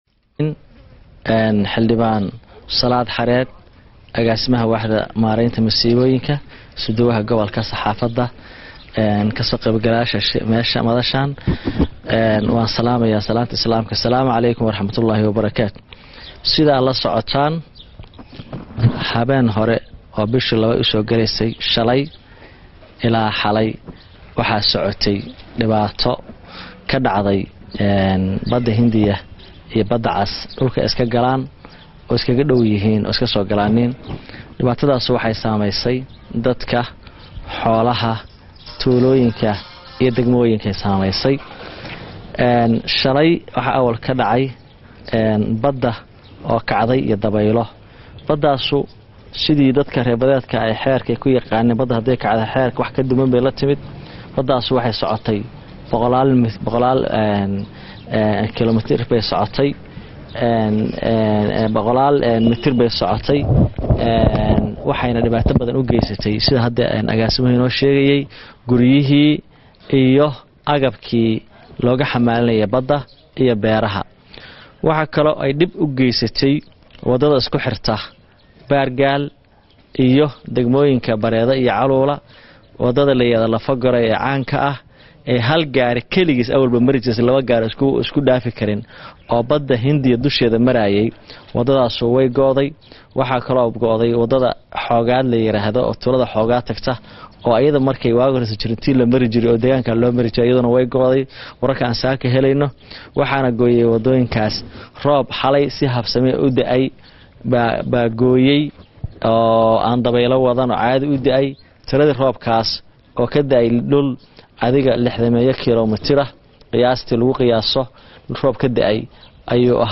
DHAGEYSO: Shirka Jaraa'id ee Wasiir ku xigeenka Arrimaha Gudaha Puntland;